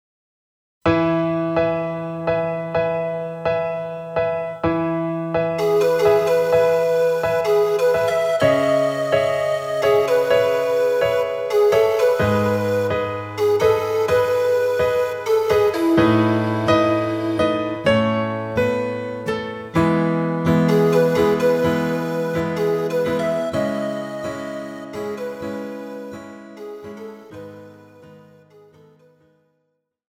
Žánr: Pop
BPM: 126
Key: E
MP3 ukázka s ML